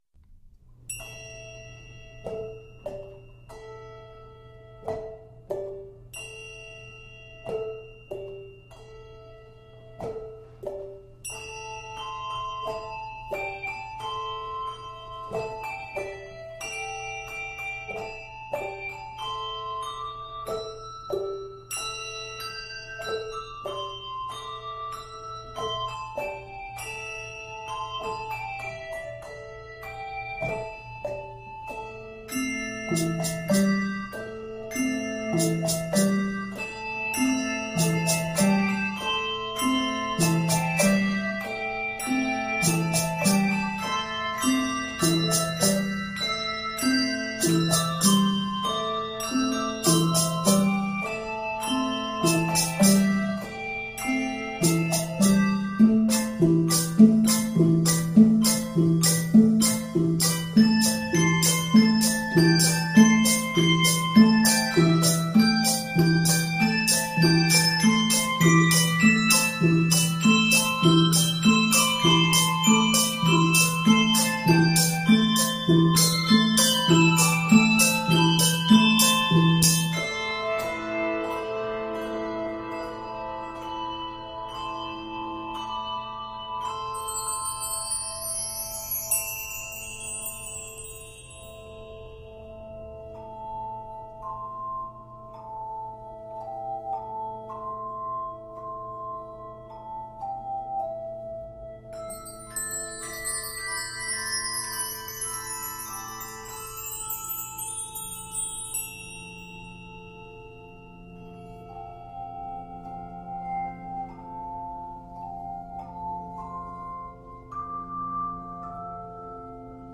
It is 54 measures in length and is scored in a minor.